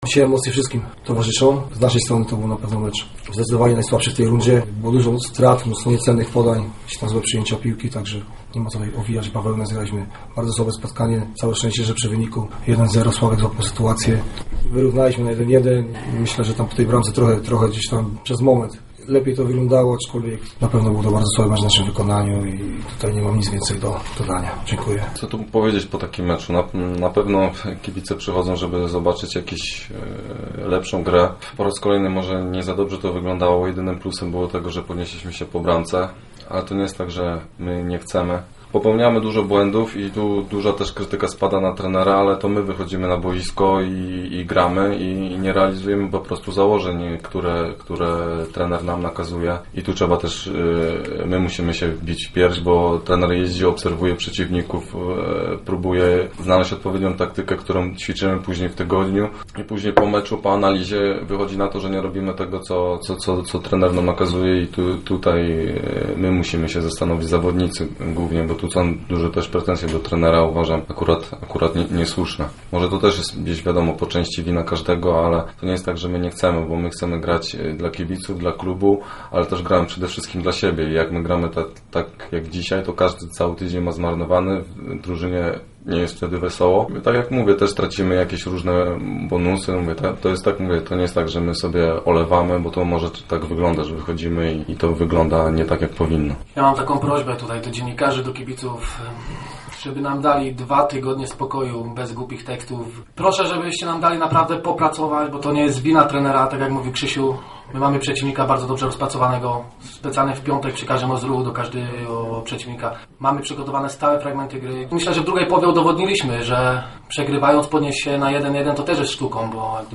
Fragmenty pomeczowej konferencji prasowej.